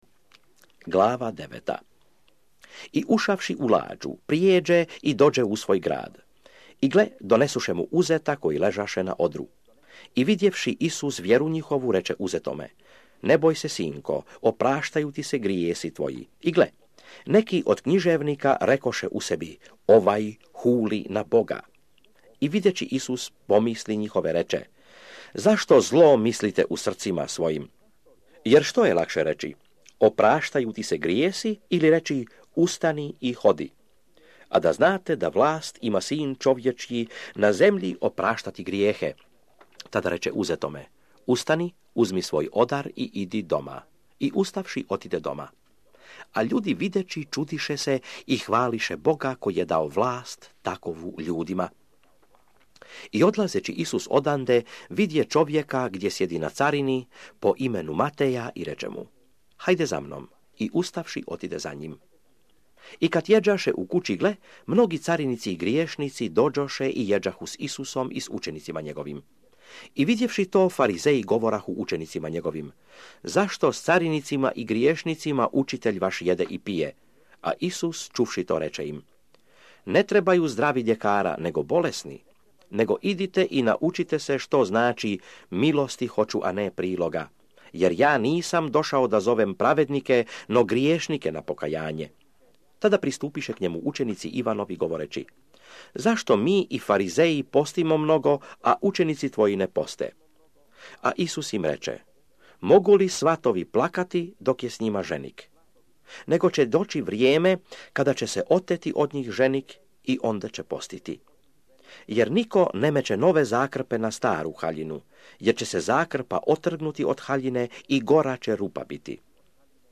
Audio DK hrvatski zapis